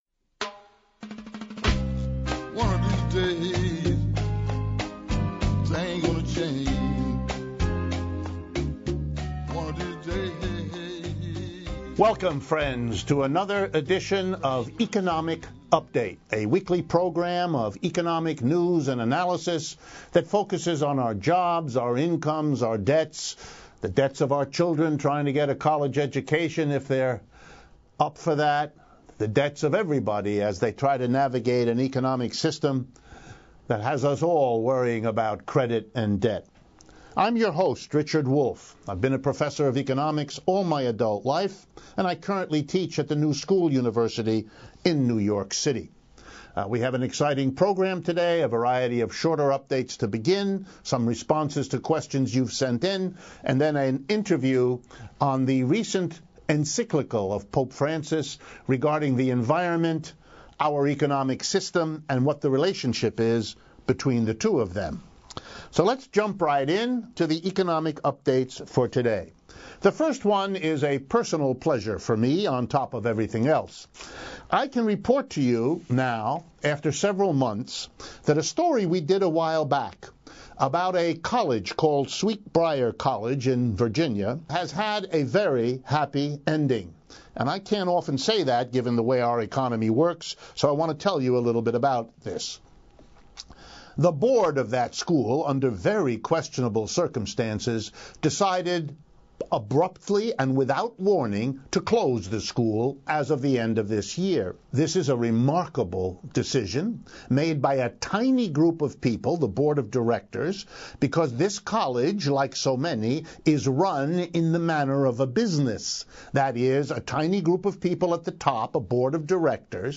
The second half of the show features an interview with veteran reporter